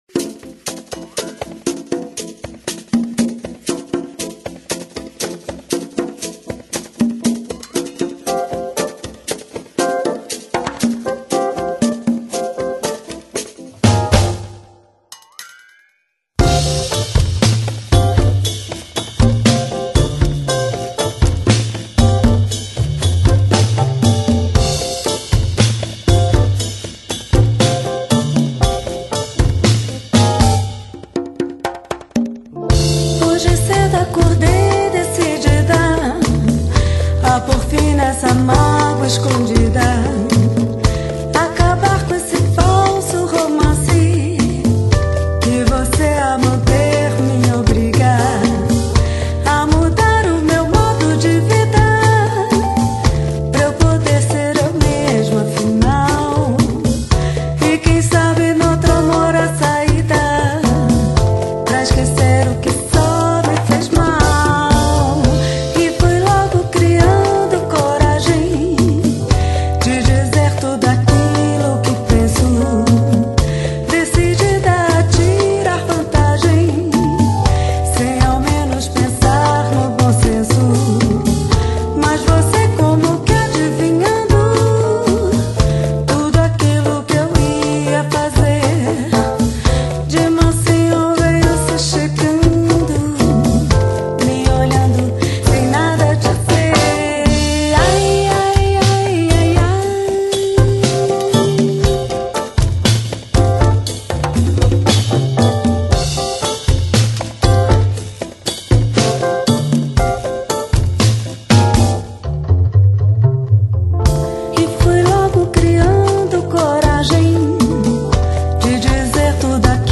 Bossa Nova Para Ouvir: Clik na Musica.